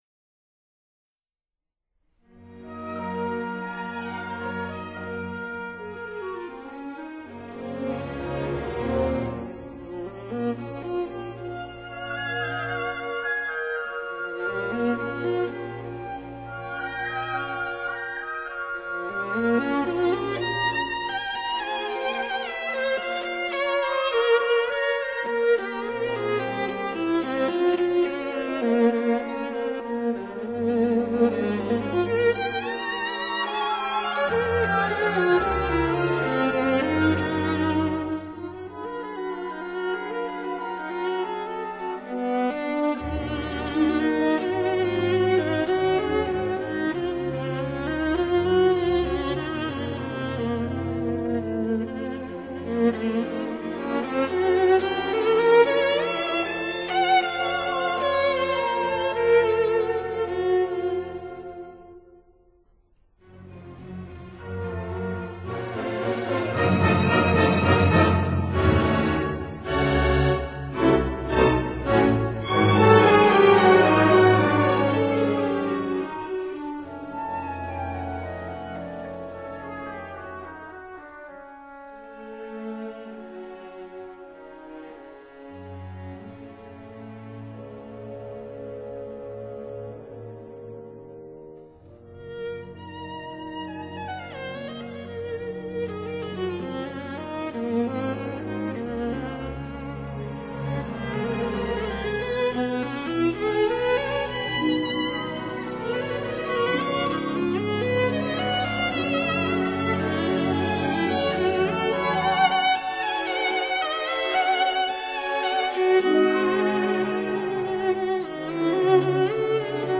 Version for Viola and Orchestra
ballet
viola